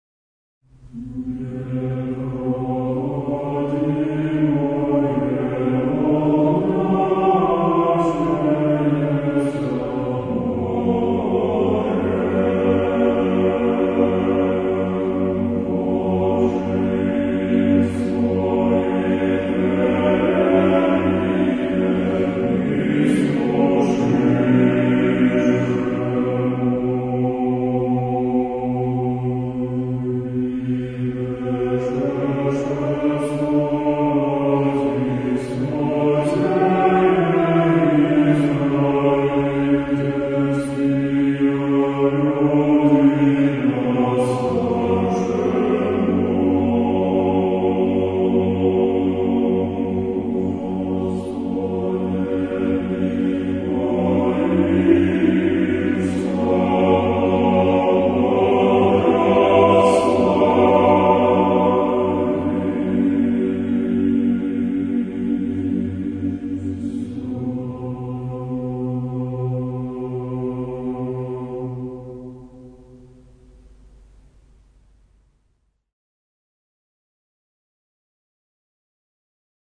Canon, Irmos, Canticle One, Tone 2